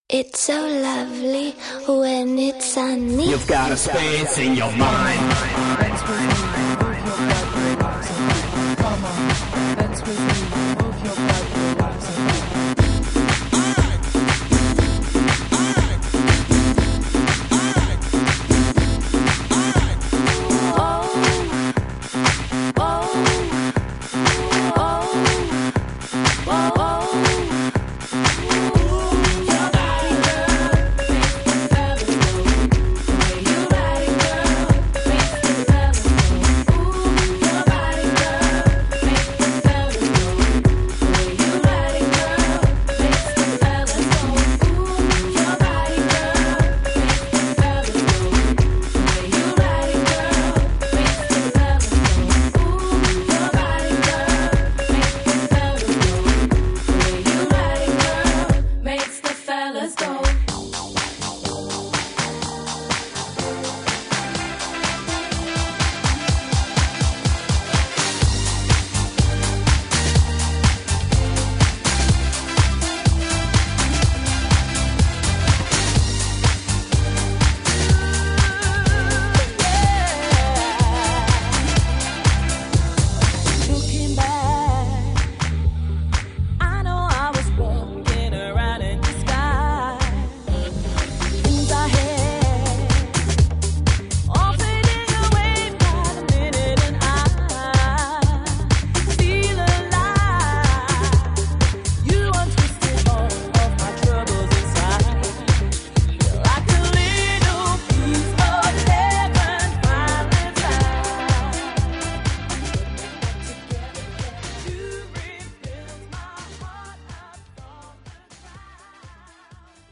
First mix down (2:00) 80kbps 32.0 Khz   (1.1 Mb) ( !